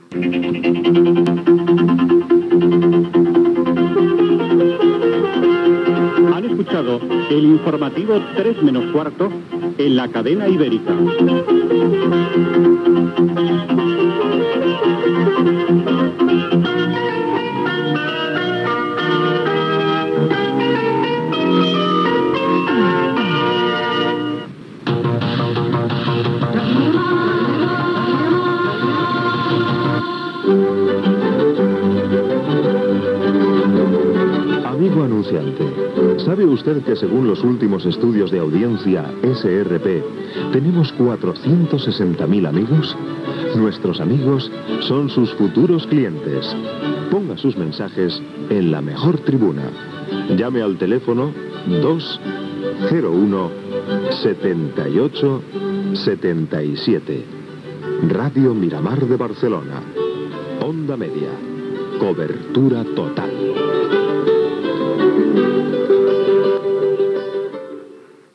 Careta de sortida del pograma, sintonia de la cadena, indicatiu i promoció "Anúnciese en Radio Miramar, onda media, cobertura total"